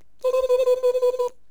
indiattack4.wav